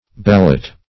Ballot \Bal"lot\ (b[a^]l"l[u^]t), n. [F. ballotte, fr. It.